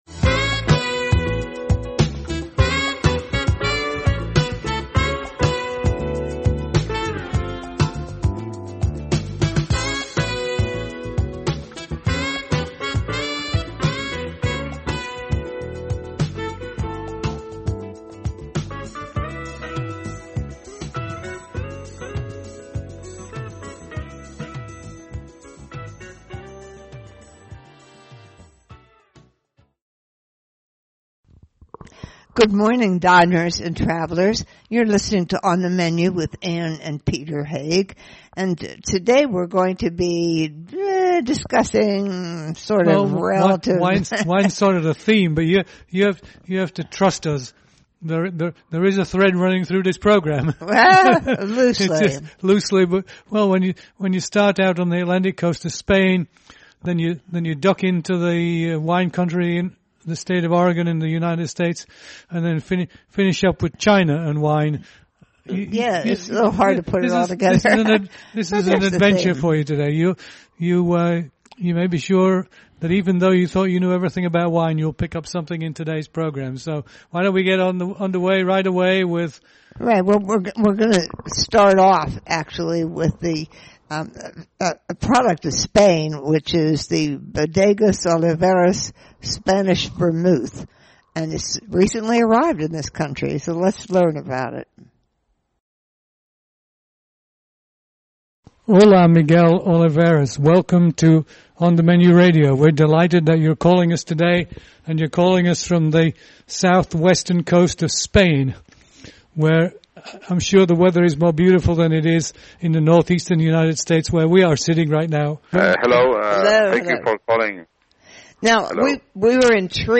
Play Rate Listened List Bookmark Get this podcast via API From The Podcast A husband and wife duo, they interview chefs, restaurateurs, hoteliers, authors, winemakers, food producers, cookware and kitchen gadget makers and other culinary luminaries.